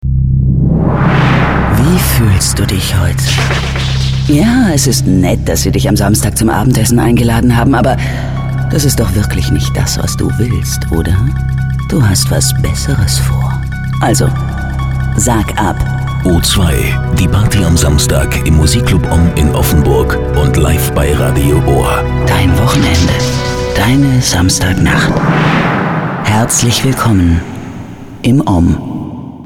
Unter den Sprecherinnen in Deutschland war Franziska Pigulla diejenige, mit der wohl tiefsten Sprecherinnenstimme. Ihre warme, einzigartige Stimme und Sprechweise gaben allen Produktionen etwas sehr Spezielles, mit einem 100-prozentigem Wiedererkennungswert, zahlreiche Marken erfolgreich als Werbestimme nutzten.
H Ö R B E I S P I E L E – in der finalen Tonmischung: